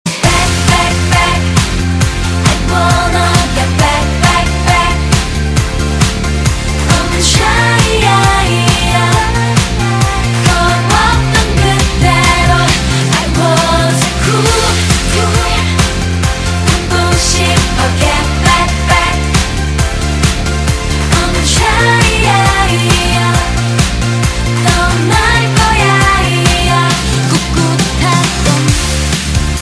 M4R铃声, MP3铃声, 日韩歌曲 35 首发日期：2018-05-14 13:15 星期一